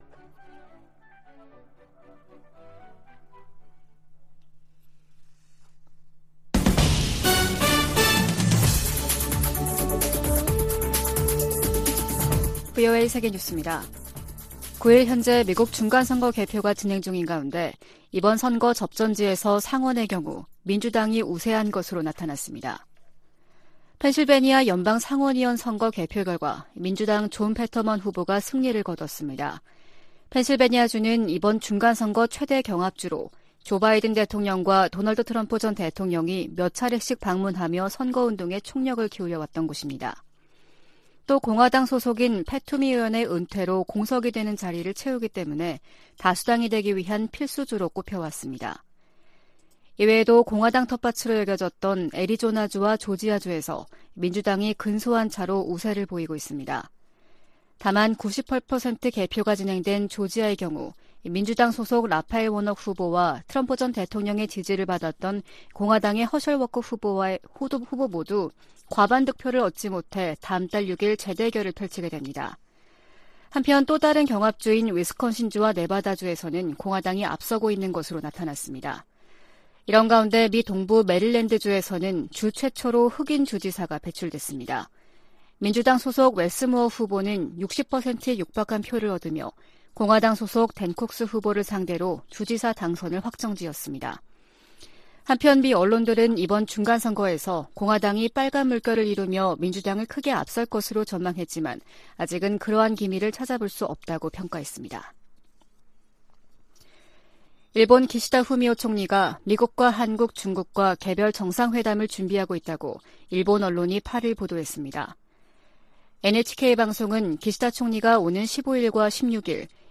VOA 한국어 아침 뉴스 프로그램 '워싱턴 뉴스 광장' 2022년 11월 10일 방송입니다. 북한이 9일 오후 평안남도 숙천 일대에서 동해상으로 단거리 탄도미사일 1발을 또 발사했습니다. 8일 투표를 끝낸 미국 중간선거는 개표를 진행하고 있습니다. 공화당이 의회를 장악할 경우 미국의 한반도 정책에 어떤 변화가 생길지 주목되고 있습니다. 미 국방부는 북한이 러시아에 포탄을 제공하고 있다는 기존 입장을 재확인하며 상황을 계속 주시할 것이라고 밝혔습니다.